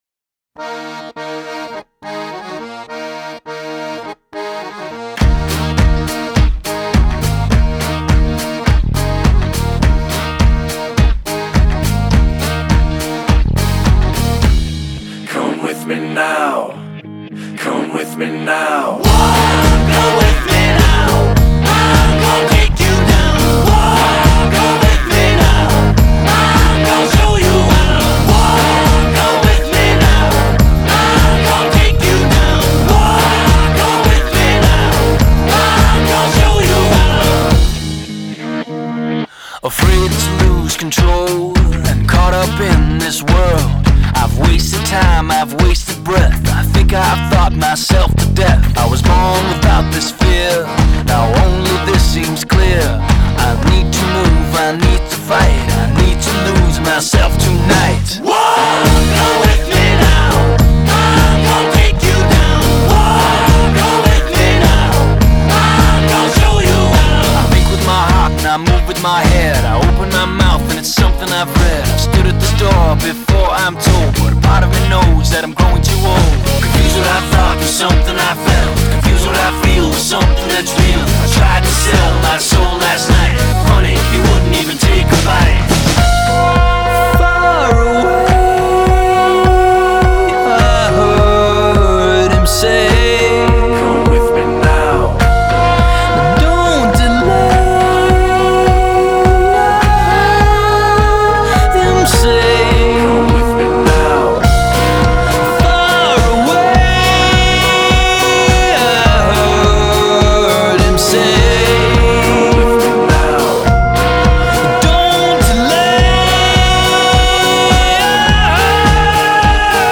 خیلی آهنگ انرژیکیه گوش بدین ;)